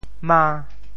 潮州 ma1 文 对应普通话: mā 潮州 ma5 白 对应普通话: mā ①称呼母亲：孩子的～ | 谢谢～ | 好～ ～。
ma5.mp3